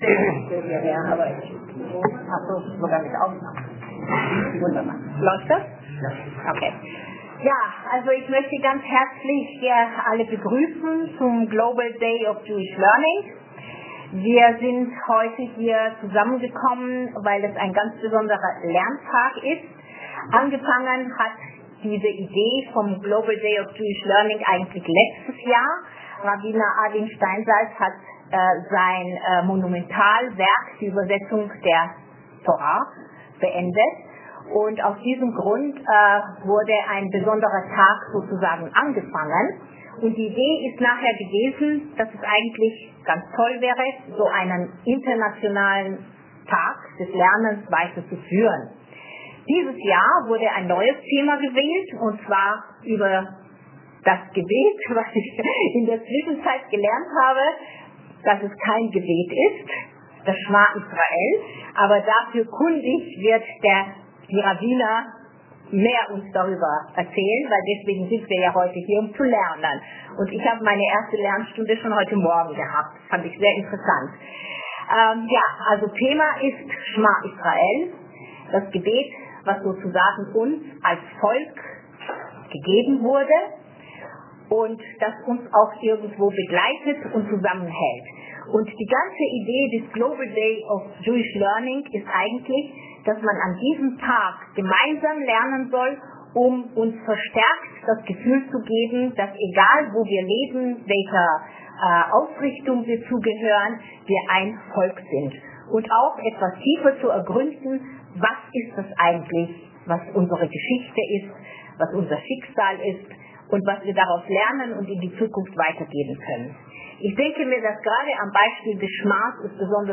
Die ethische Dimension des Schma Jissraëls (Audio-Schiur)